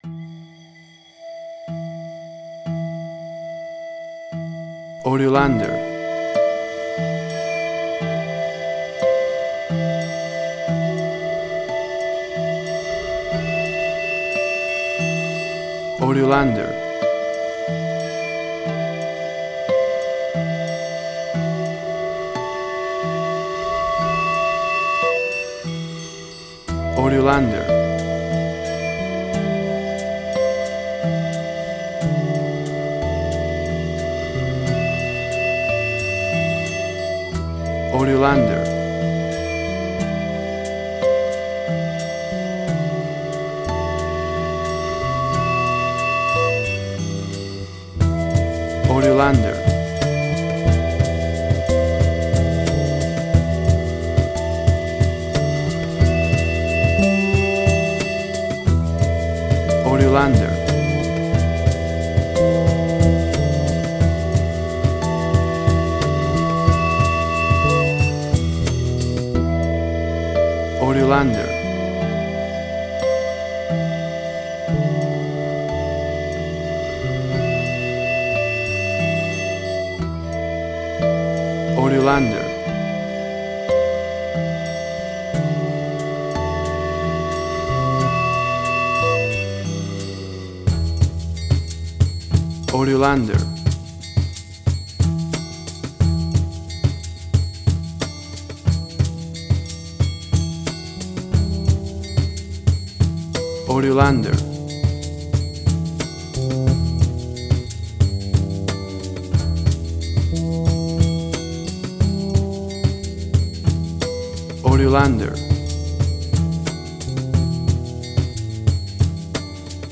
WAV Sample Rate: 24-Bit stereo, 48.0 kHz
Tempo (BPM): 90